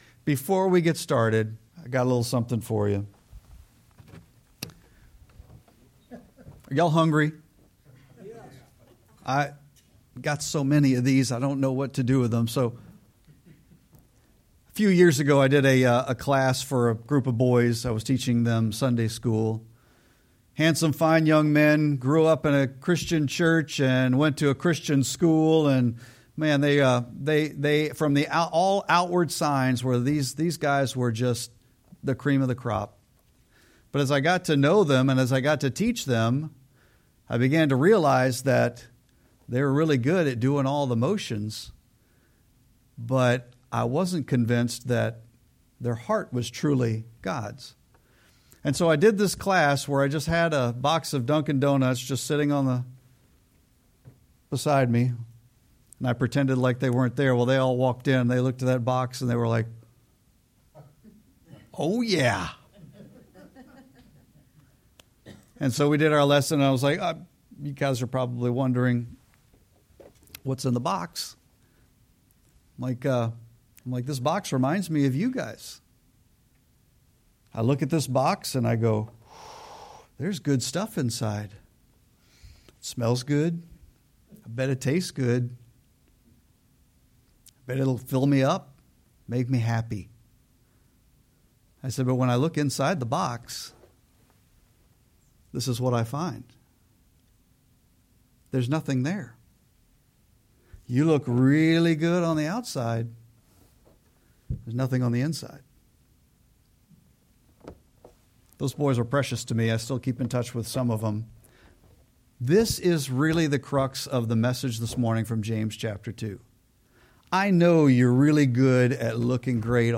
Sermon-7-13-25.mp3